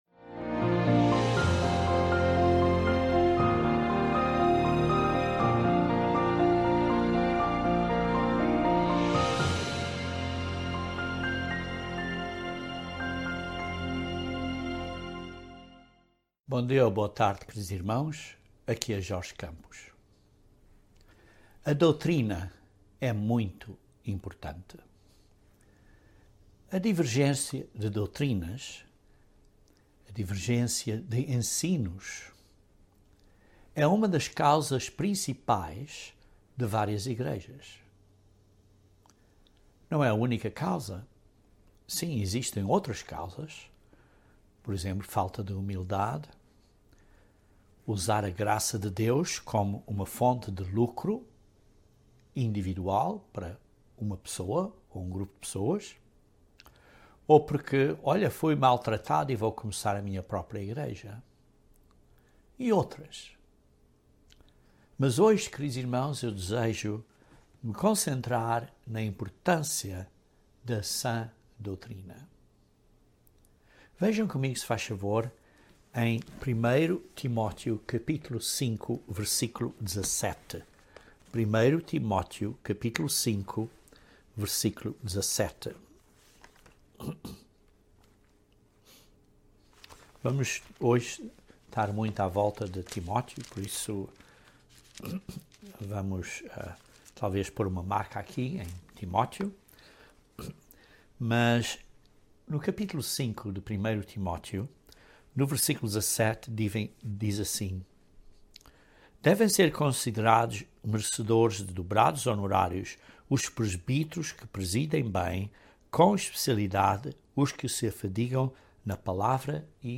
A doutrina é muito importante. Este sermão aborda a importância da Sã Doutrina e como temos que combater contra heresias que virão.